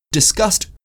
Can you differentiate the words discussed and disgust as produced by native speakers in natural sentences?
discussed or disgust? (USA)